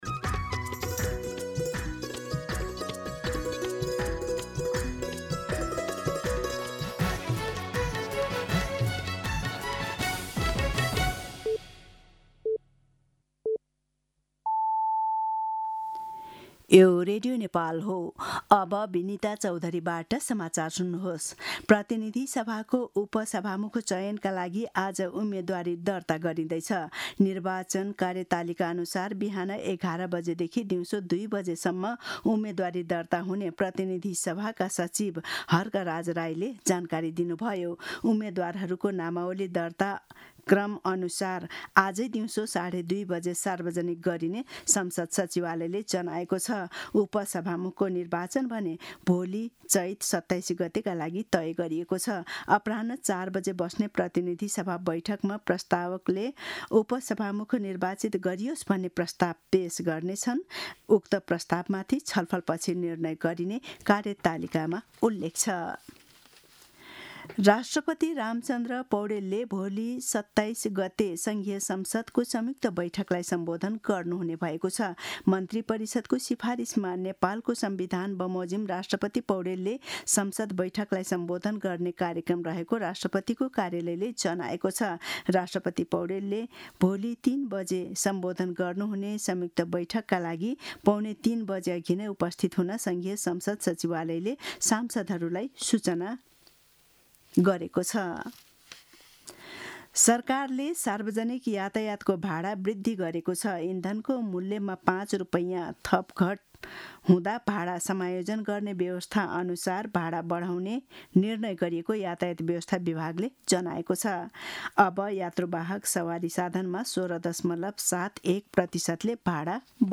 मध्यान्ह १२ बजेको नेपाली समाचार : २६ चैत , २०८२